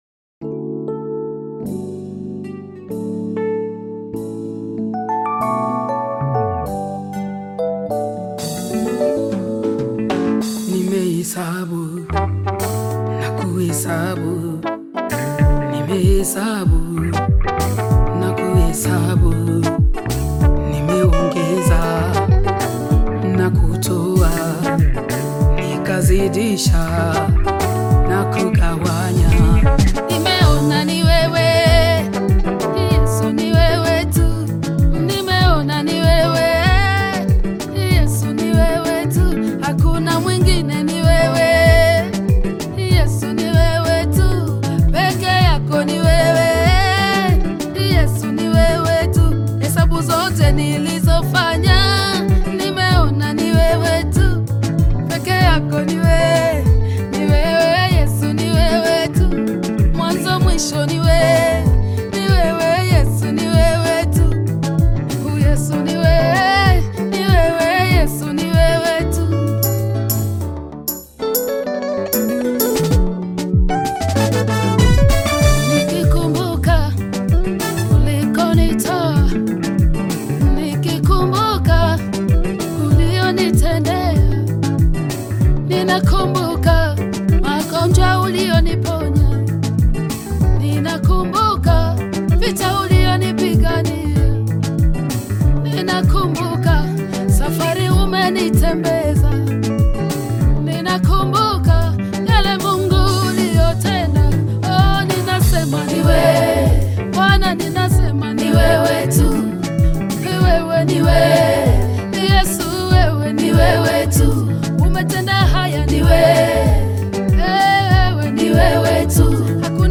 Both praise and worship songs.